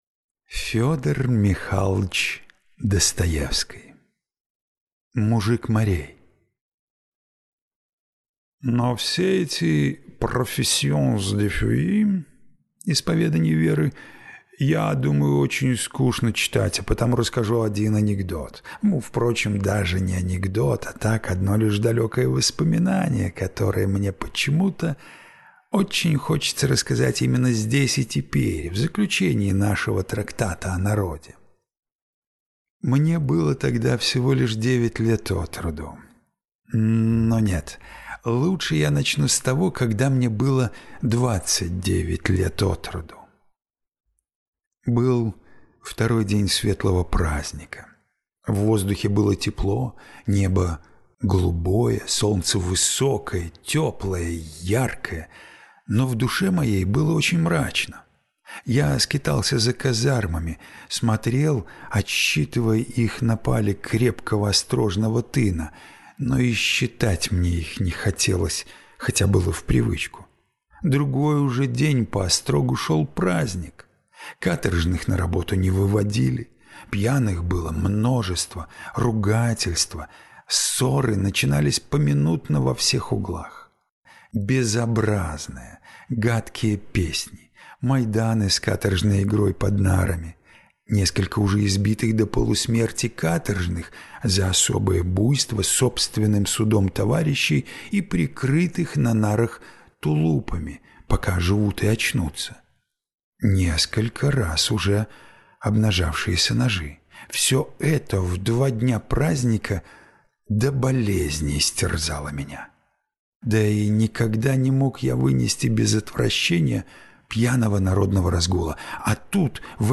Аудиокнига Мужик Марей | Библиотека аудиокниг